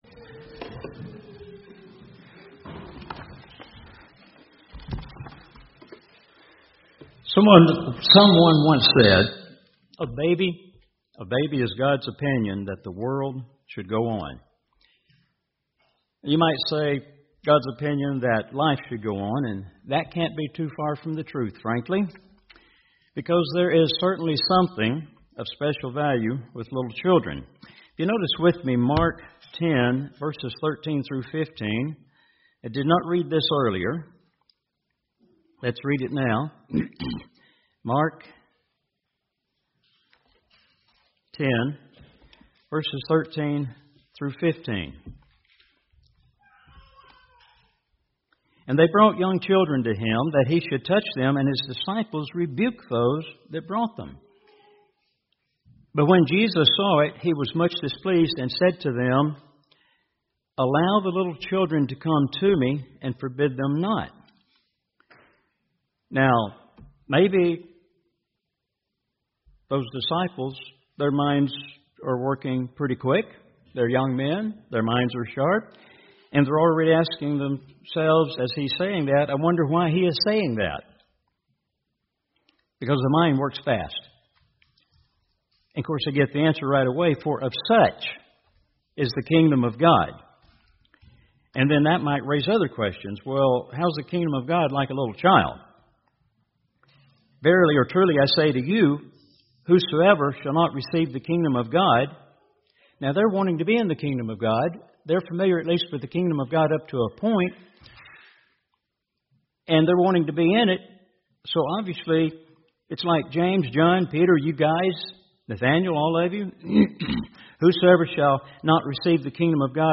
If you have ever had any questions about this scripture, this is the sermon for you.